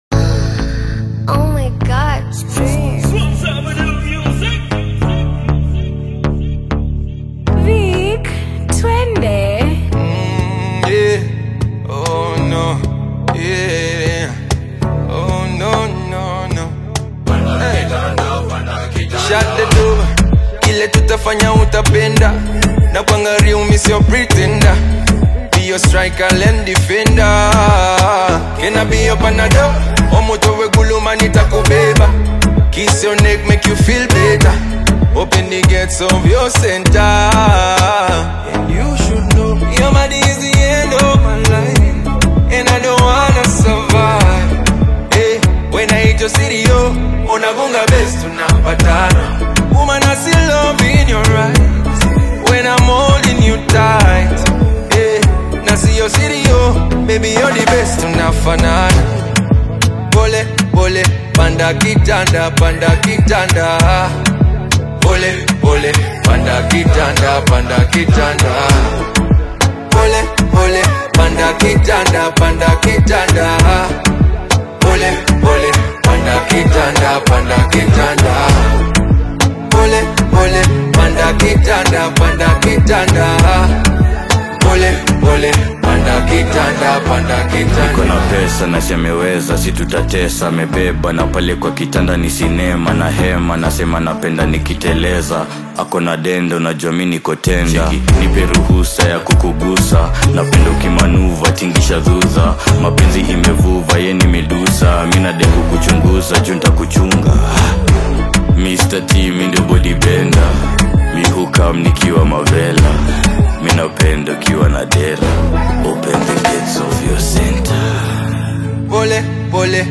a playful and romantic song
Ugandan music